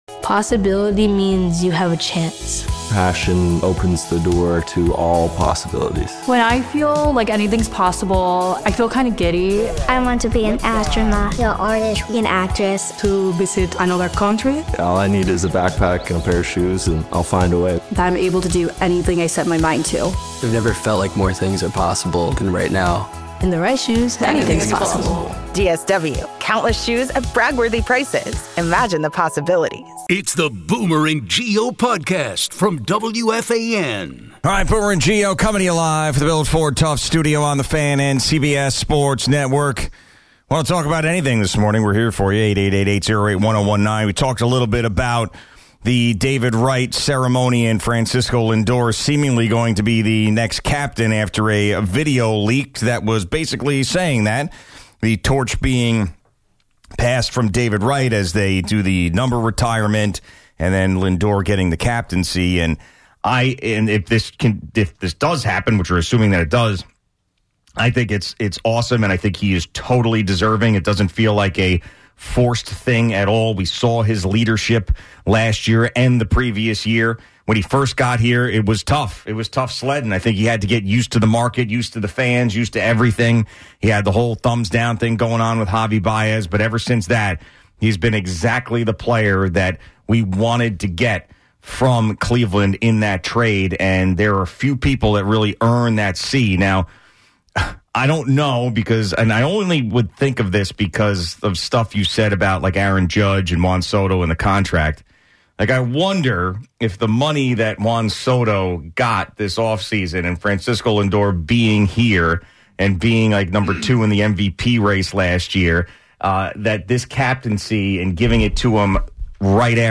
All right, Boomer and Geo coming you live for the Bill of Ford Tough Studio on the fan 0:07.9 and CBS Sports Network.